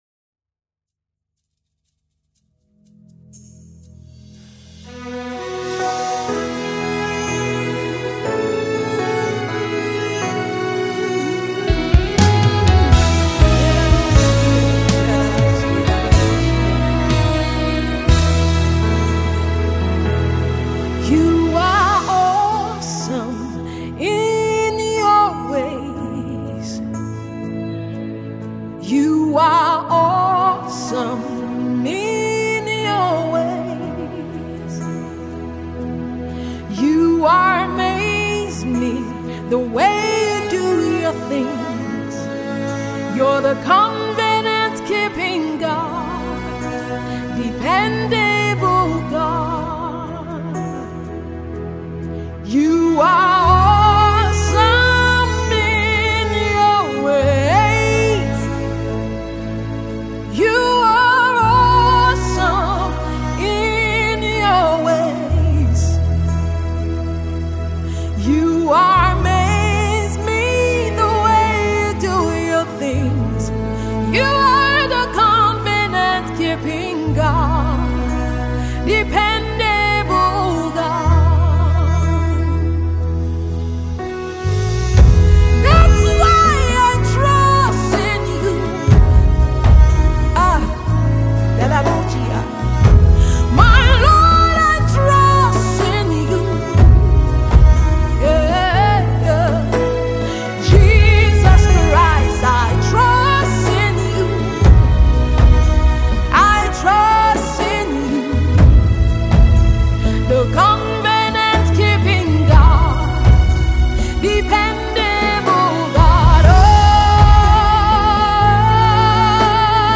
gospel music
worship song